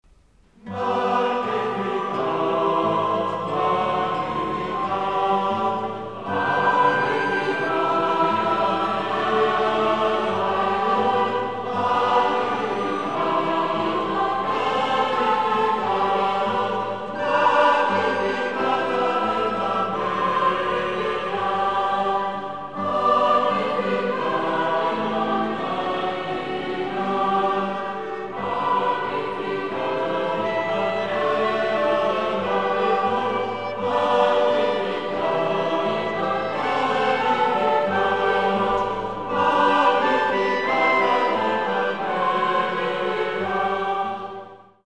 Voicing: Unison; SATB